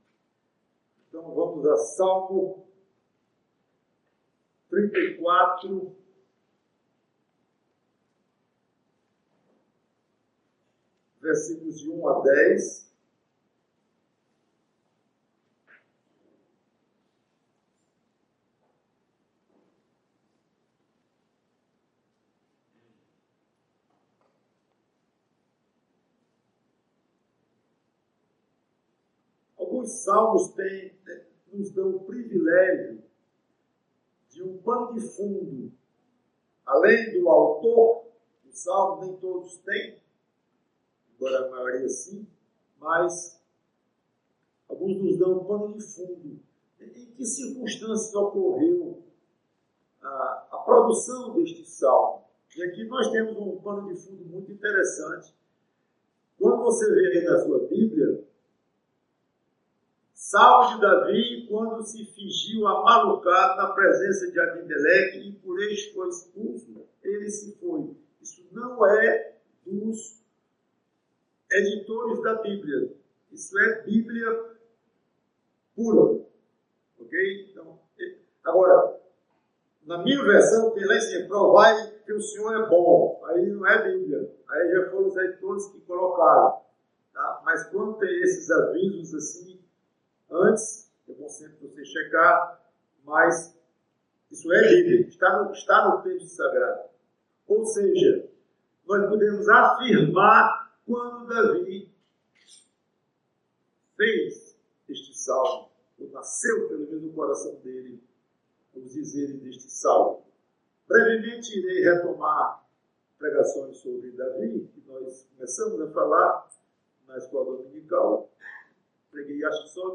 PREGAÇÃO Vibrando com o Senhor (Salmo 34 - 1a.